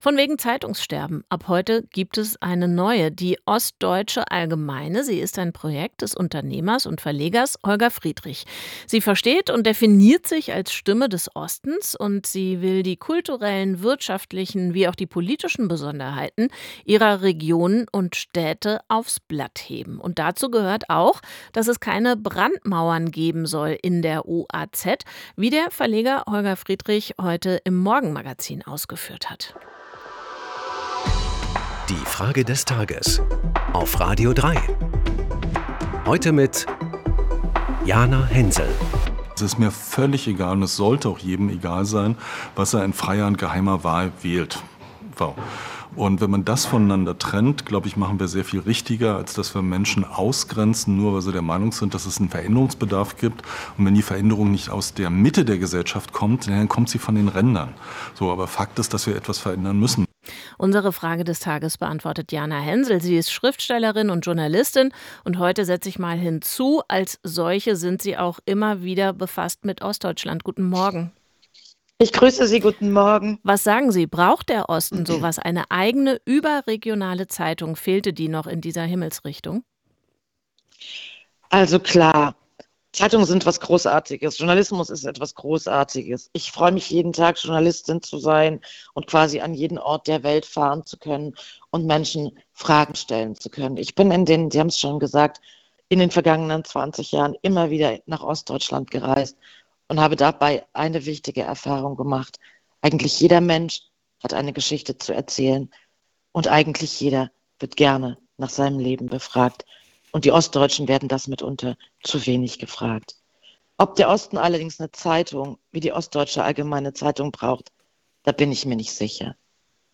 antwortet die Schriftstellerin und Journalistin Jana Hensel.